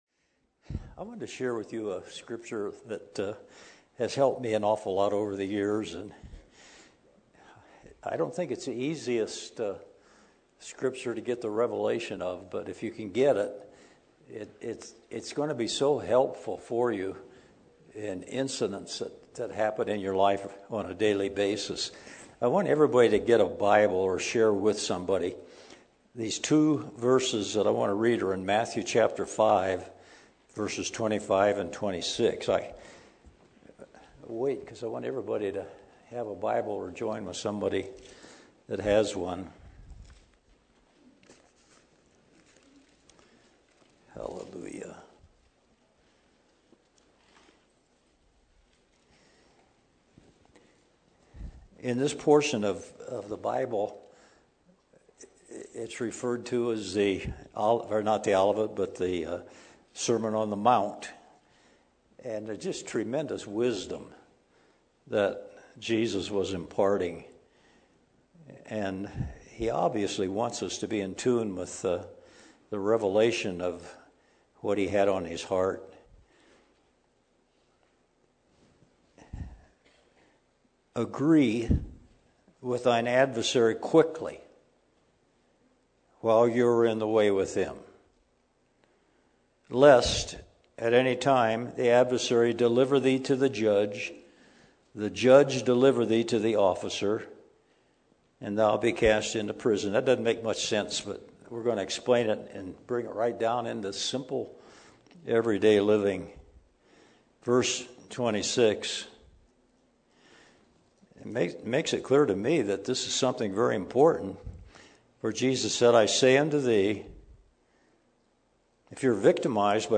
From Series: "Messages"
Testimonies, teachings, sharing.